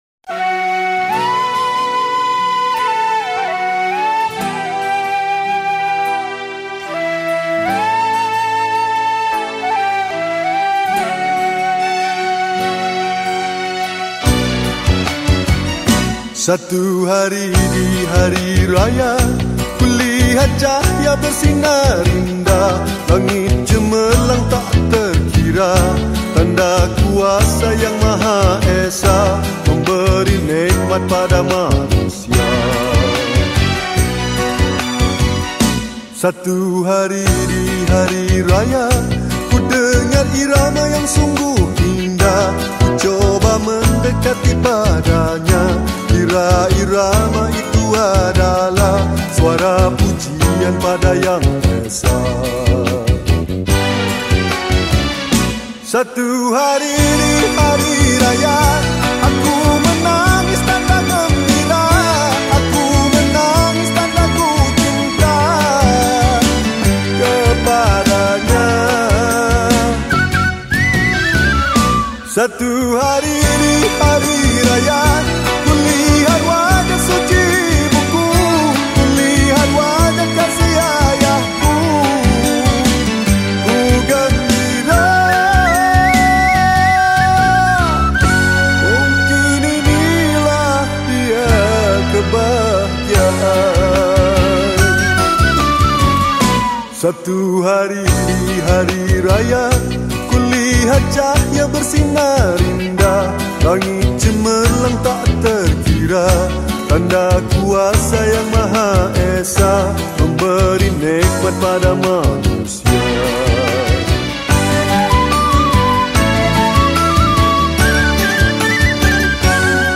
Hari Raya Song
Malay Song